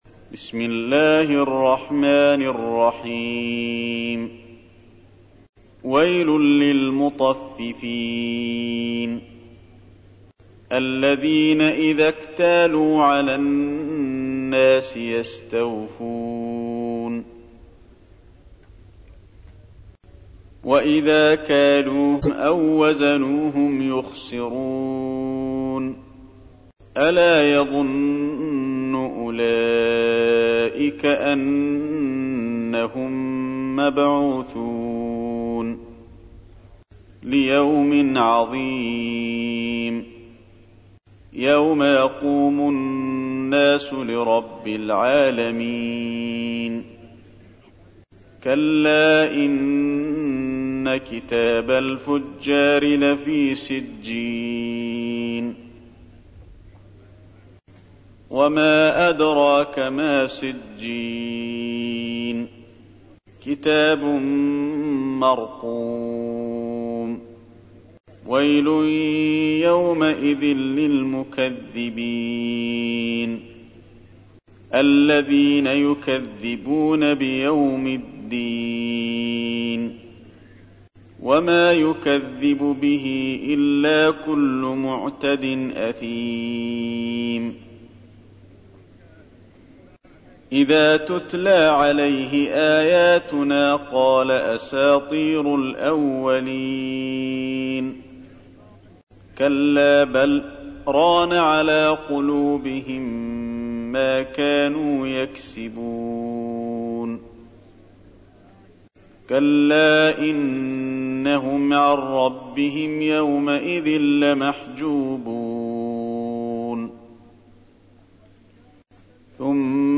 تحميل : 83. سورة المطففين / القارئ علي الحذيفي / القرآن الكريم / موقع يا حسين